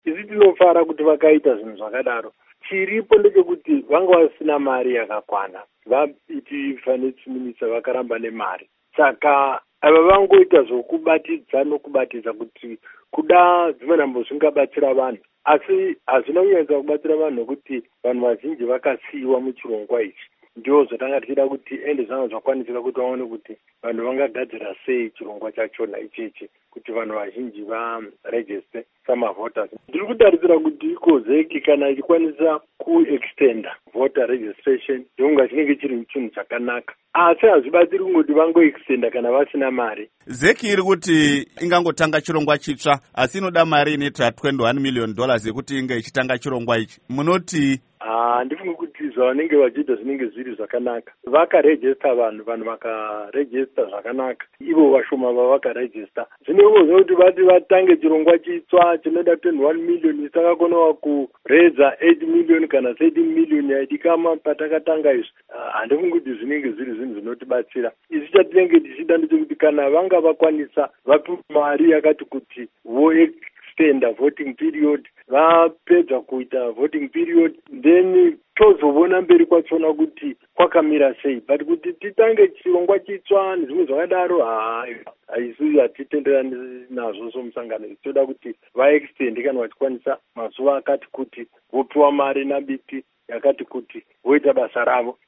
Hurukuro naVaSesil Zvidzai